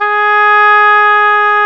WND OBOE3 03.wav